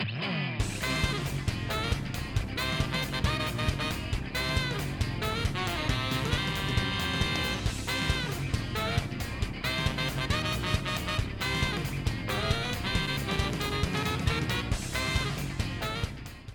Ripped from game
Fair use music sample